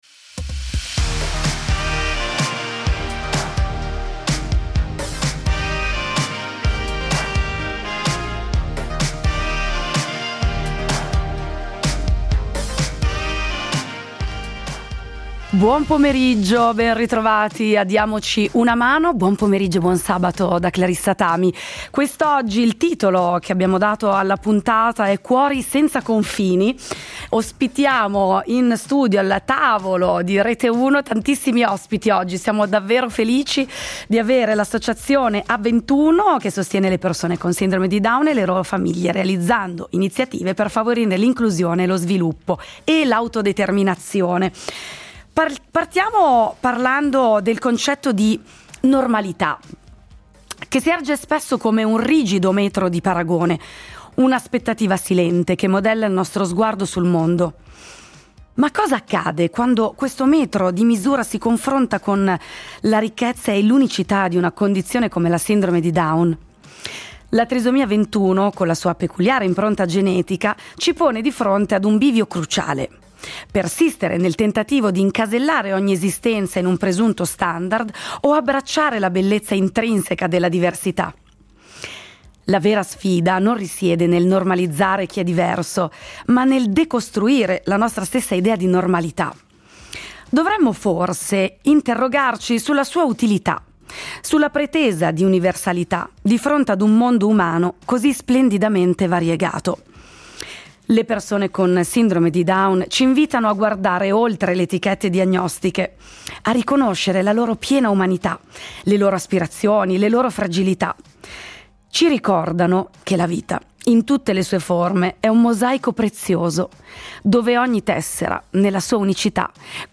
In studio le voci dell’Associazione Avventuno .